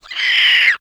CREATURE_Squeel_02_mono.wav